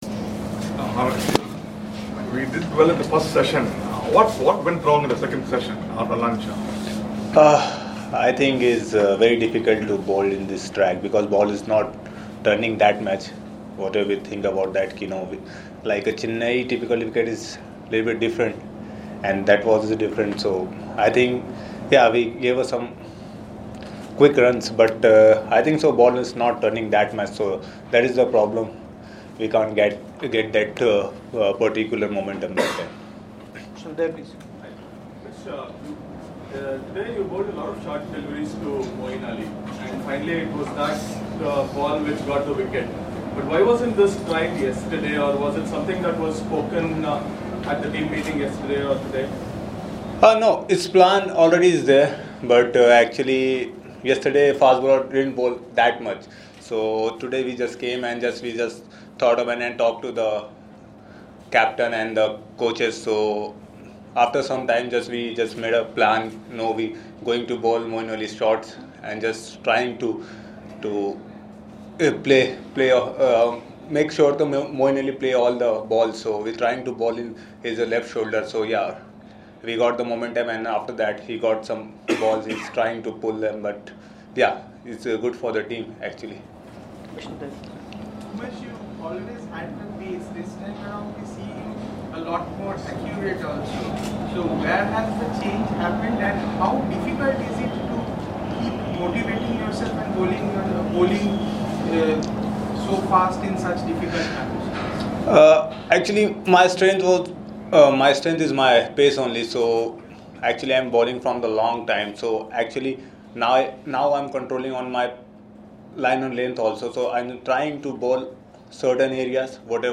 LISTEN: Indian pacer Umesh Yadav after the second day's play in Chennai